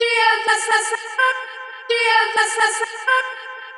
• chopped vocals ping pong house delayed (11) - F - 127.wav